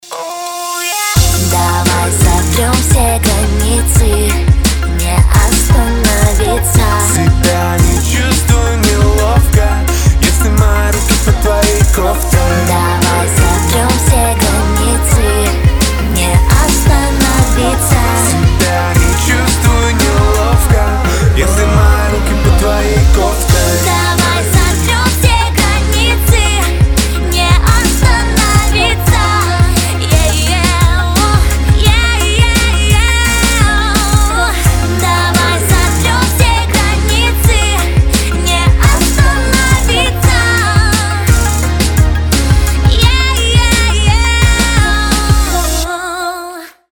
• Качество: 320, Stereo
поп
RnB